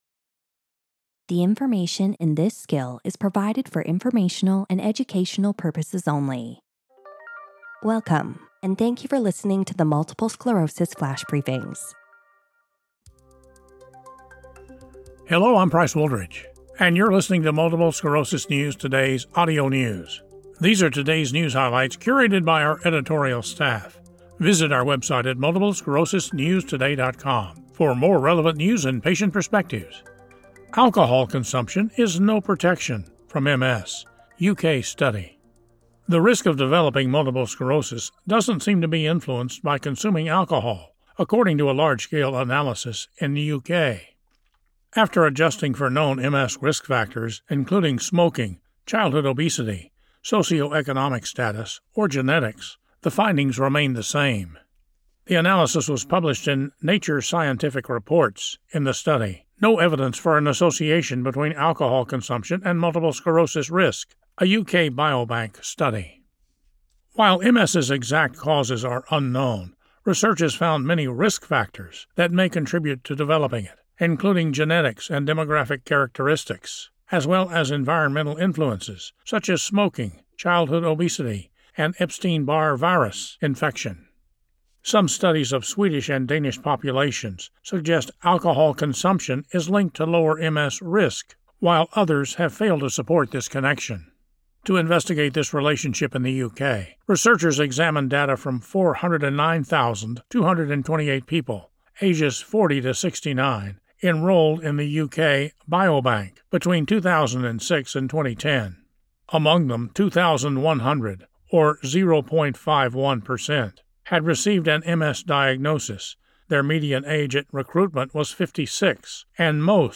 reads about a U.K. study reporting that the risk of developing multiple sclerosis was not influenced by the consumption of alcohol.